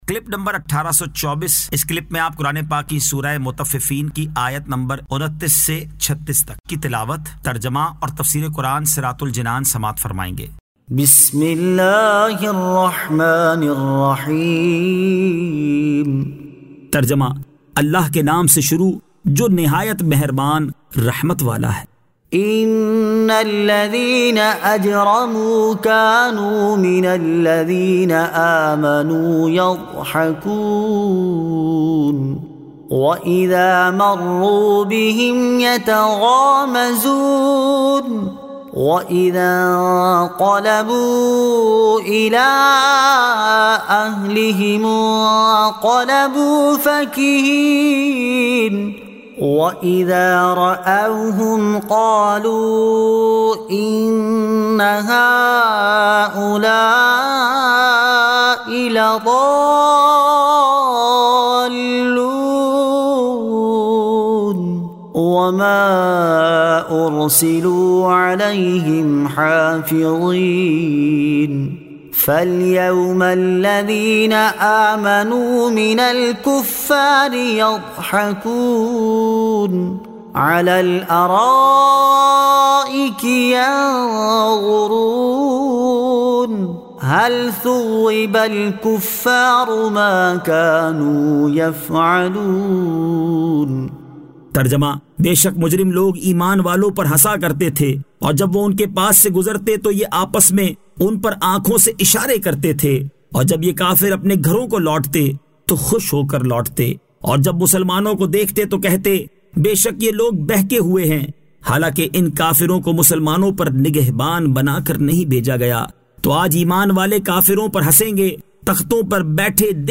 Surah Al-Mutaffifeen 29 To 36 Tilawat , Tarjama , Tafseer